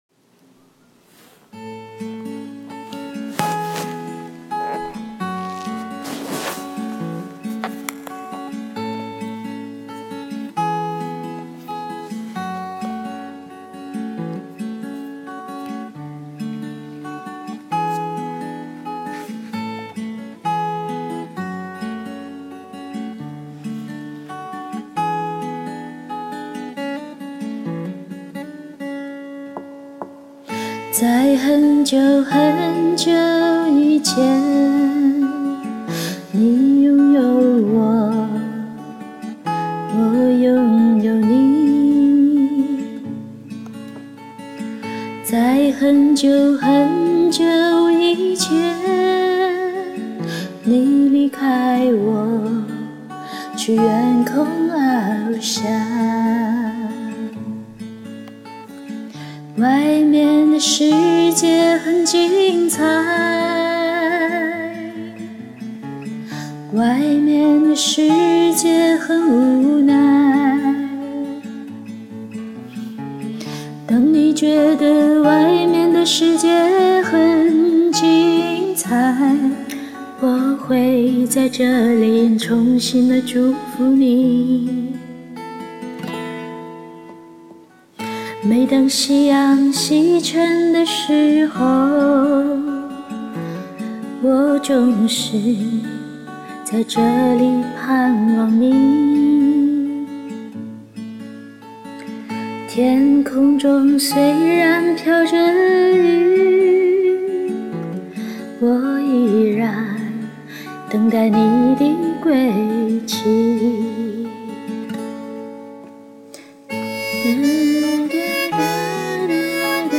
• My first time hearing a female version.